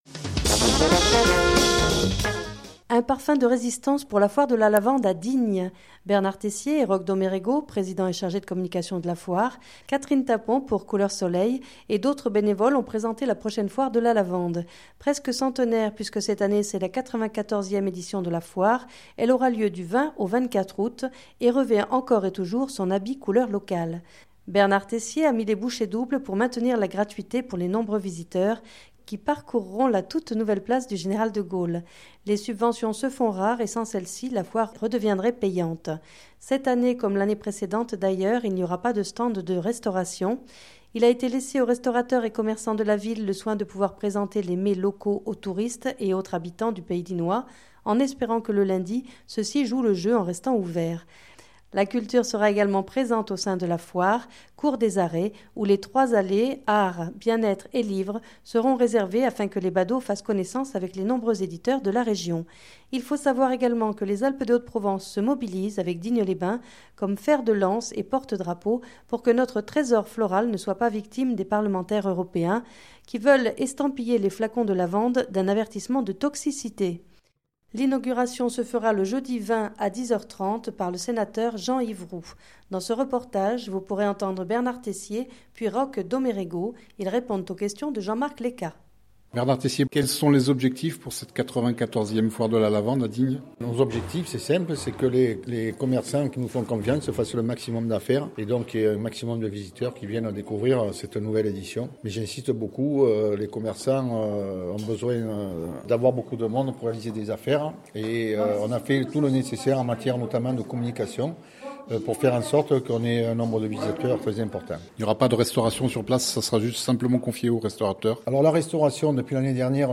Dans ce reportage